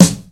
• Old School Hot Hip-Hop Acoustic Snare Sound F# Key 227.wav
Royality free acoustic snare tuned to the F# note. Loudest frequency: 1761Hz
old-school-hot-hip-hop-acoustic-snare-sound-f-sharp-key-227-x4W.wav